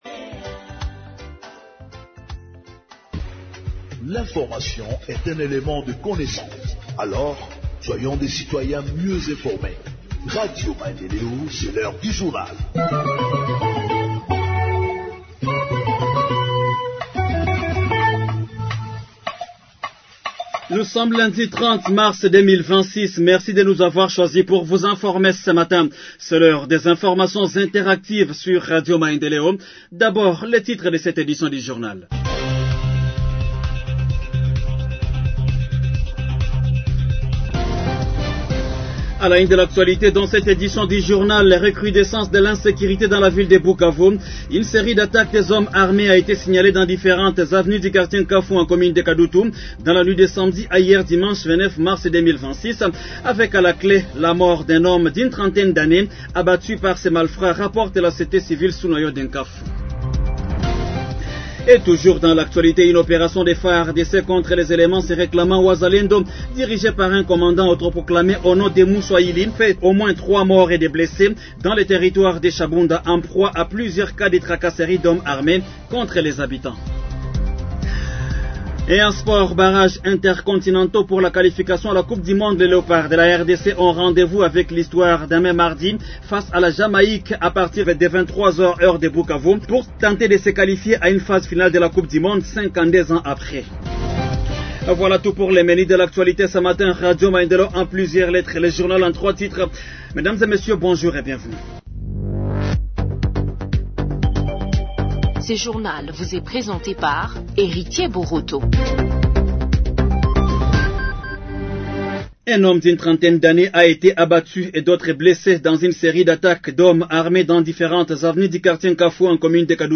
Journal en Français du 30 Mars 2026 – Radio Maendeleo